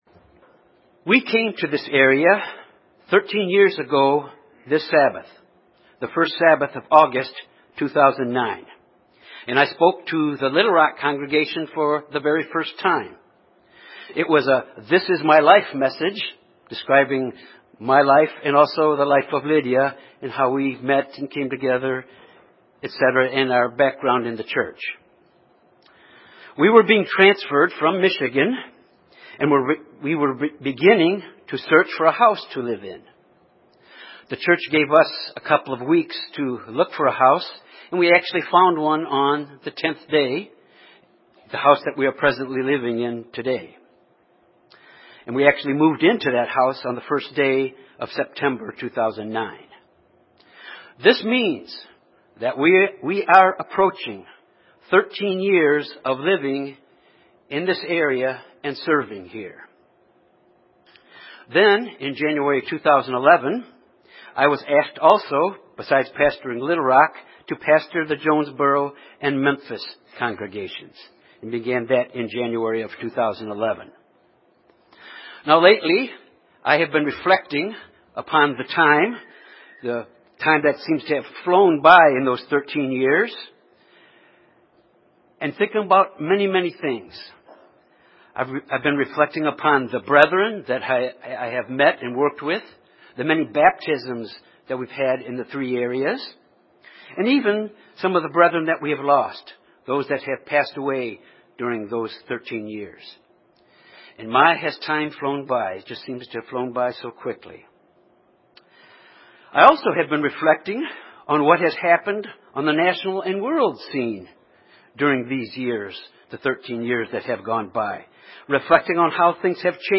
Jesus gave us signs and warnings of things to watch for the end time. This sermon examines two important things to watch now in our time to know the end time is near.